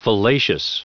Prononciation du mot fallacious en anglais (fichier audio)
Prononciation du mot : fallacious